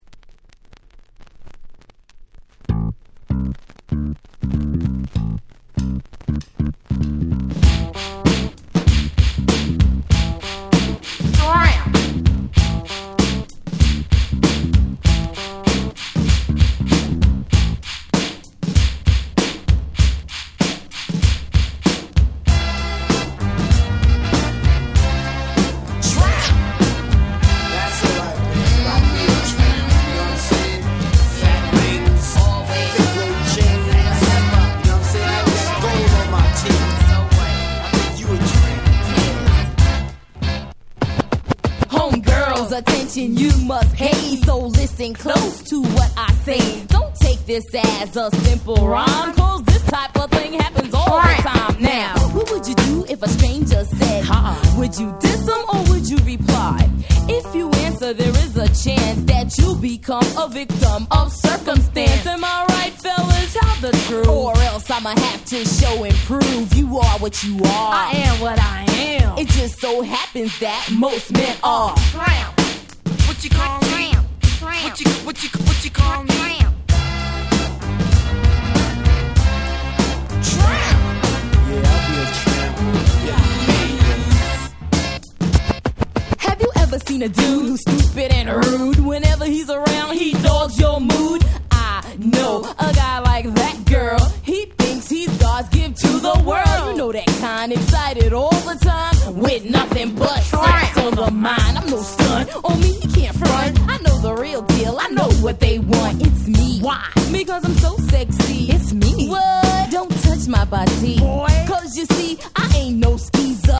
87' Super Hit Hip Hop !!